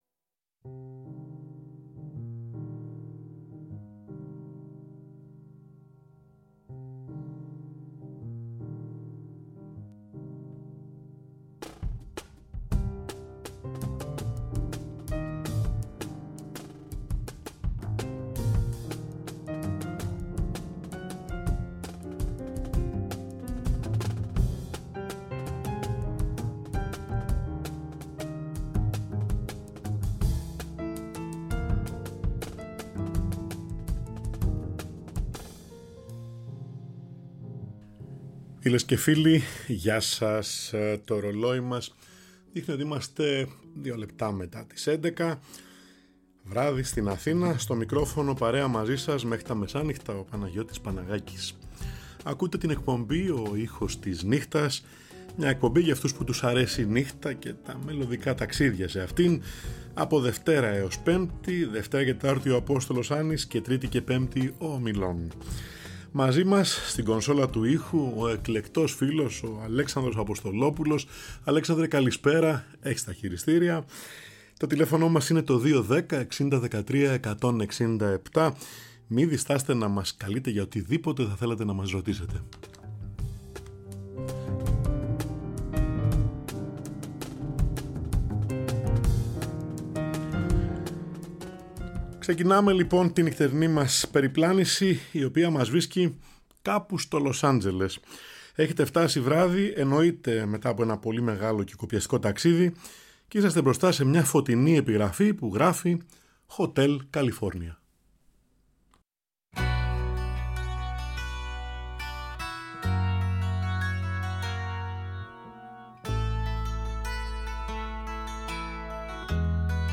Κάθε Τρίτη και Πέμπτη στις έντεκα, ζωντανά στο Τρίτο Πρόγραμμα